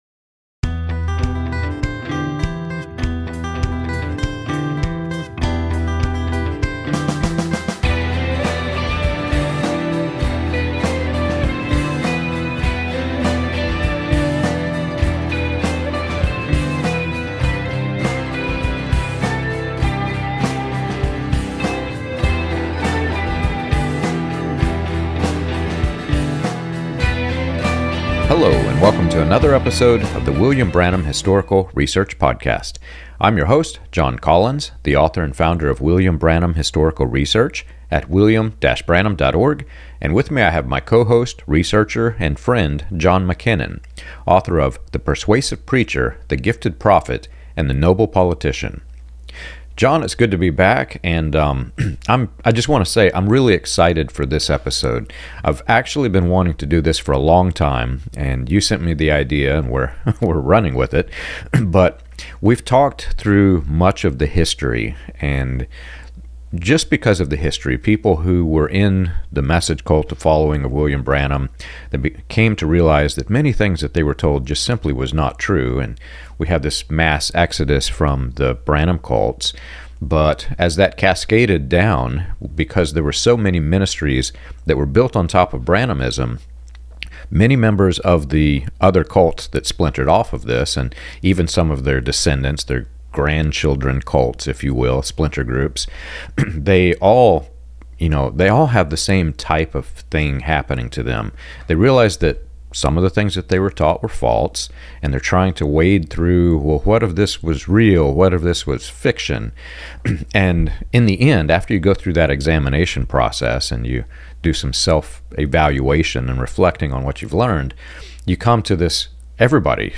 The conversation opens with an invitation for others to share their own stories of escape from groups influenced by Branhamism, and gradually unfolds into a scriptural critique showing how Branham manip